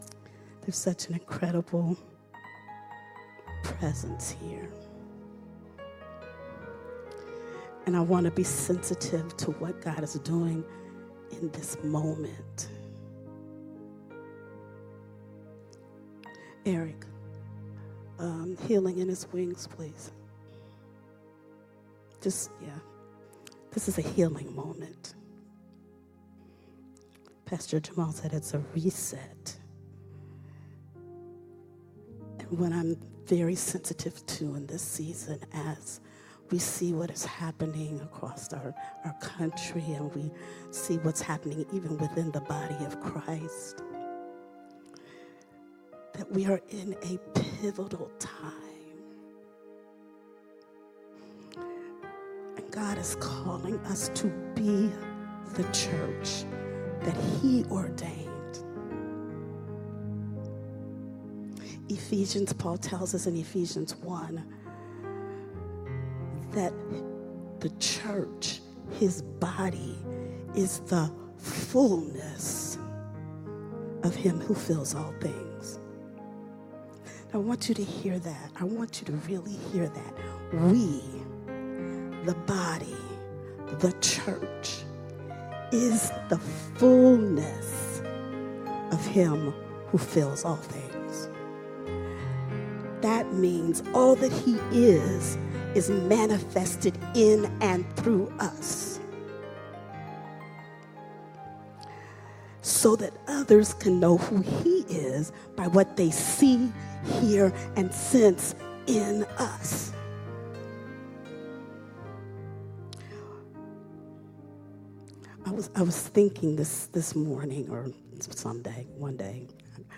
Teachings